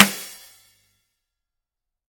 drum-hitnormal.ogg